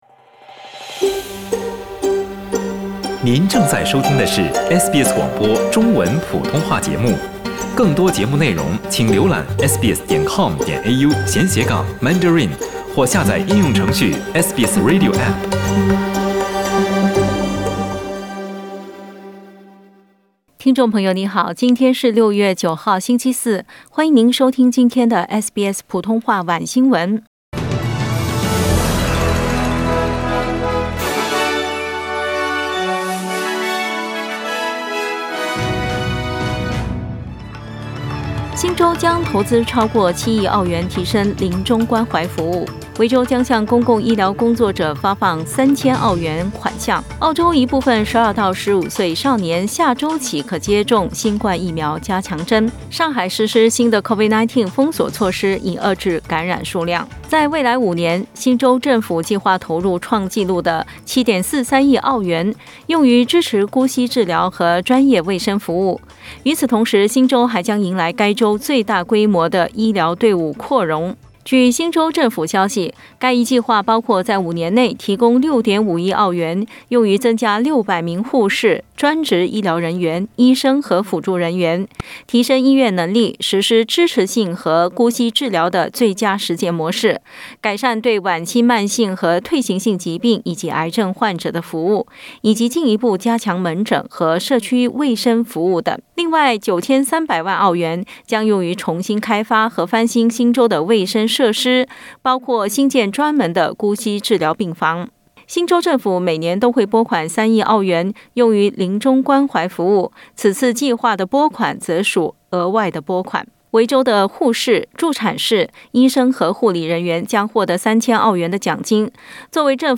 SBS晚新闻（2022年6月9日）